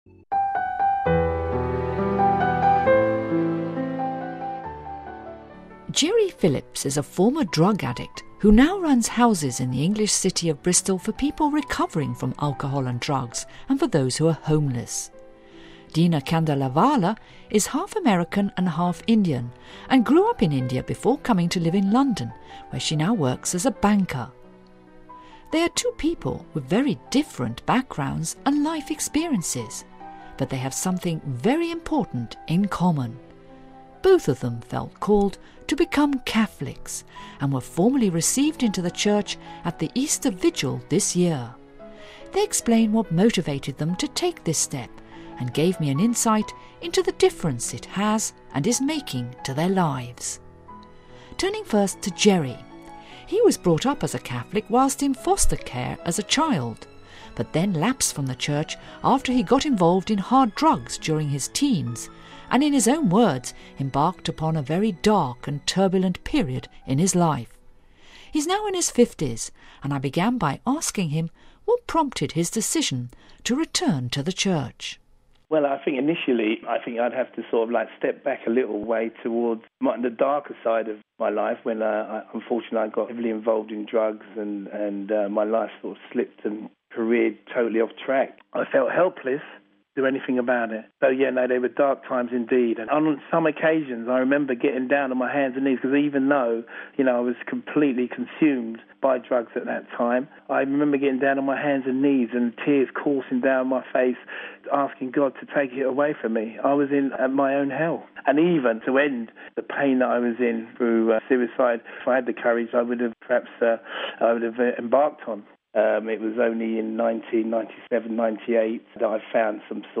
Home Archivio 2010-04-09 16:29:44 COMING HOME Two people who were formally received into the Church at the Easter Vigil this year talk about why they were drawn to Catholicism and how it has changed their life.....